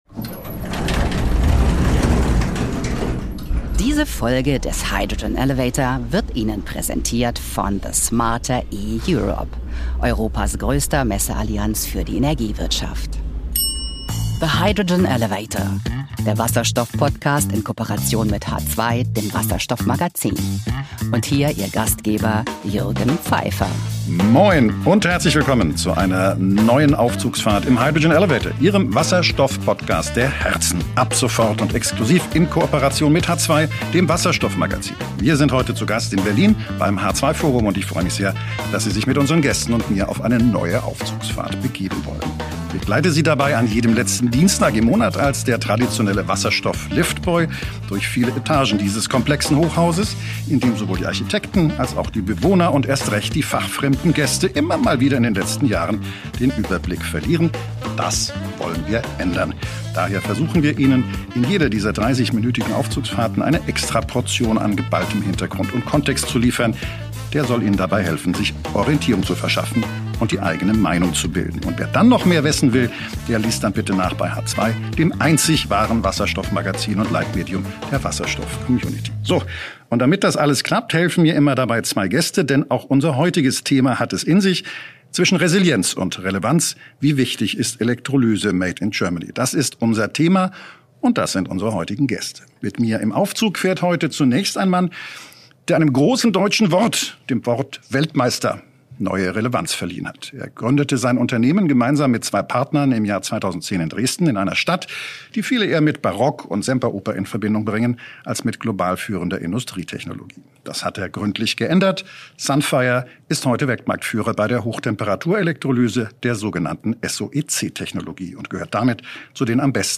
Eine Folge zwischen Industriepolitik, Energiesouveränität und dem Rennen gegen die Zeit — aufgezeichnet live am 3. März 2026 beim H2 Forum in Berlin.